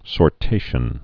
(sôr-tāshən)